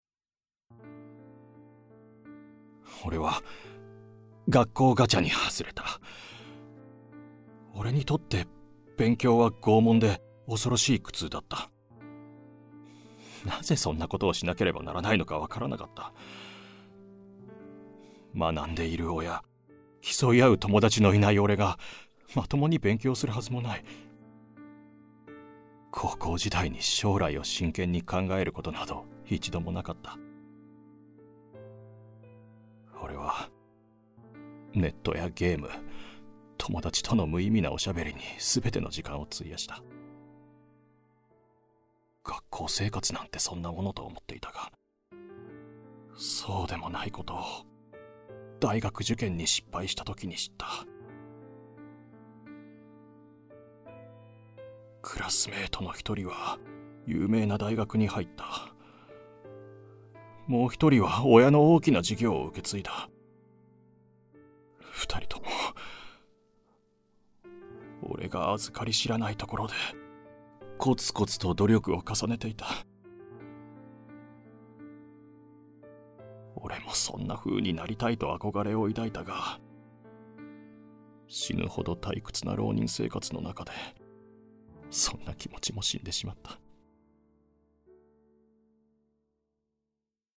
[オーディオブック] ガチャに外れた